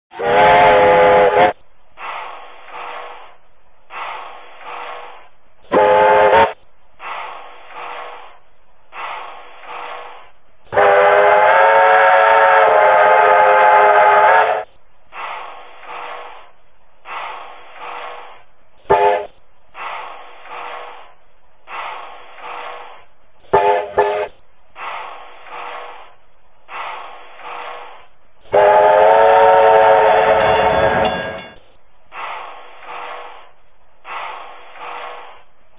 Puffing Billy 5 Chime with Brake Pump
whistles_na_5_chime_and_pump.mp3